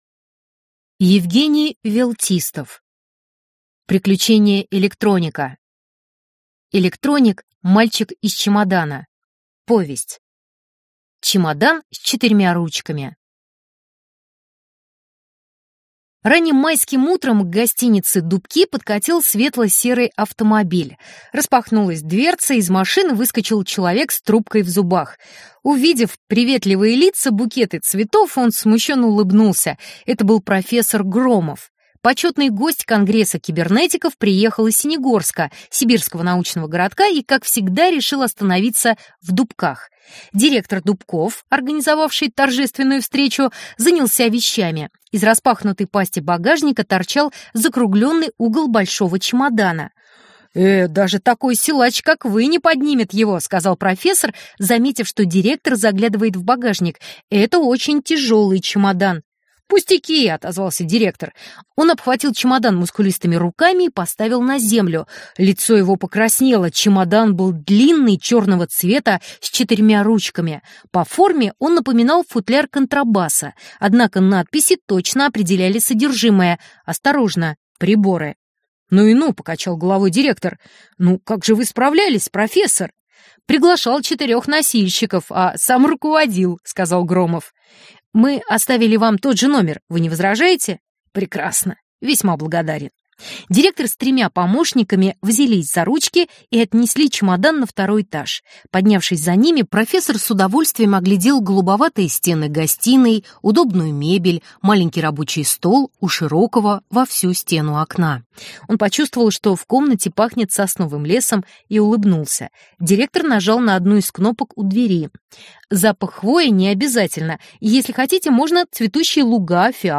Аудиокнига Электроник – мальчик из чемодана | Библиотека аудиокниг